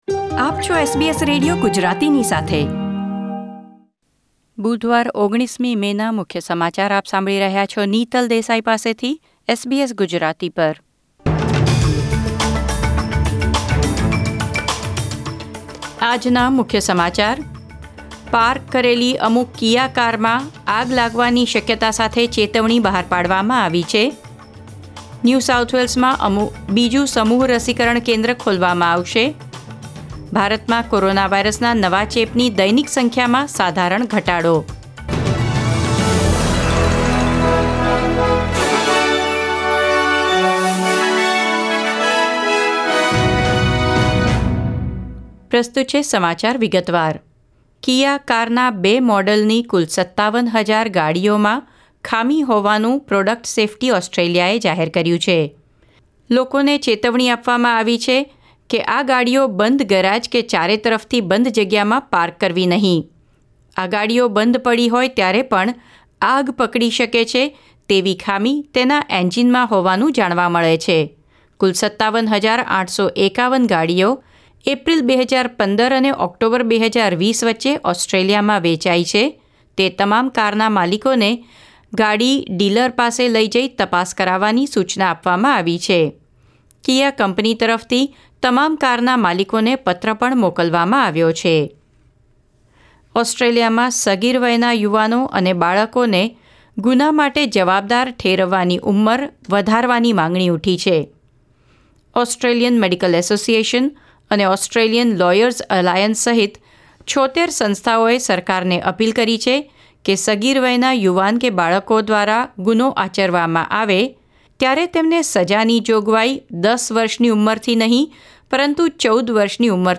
SBS Gujarati News Bulletin 19 May 2021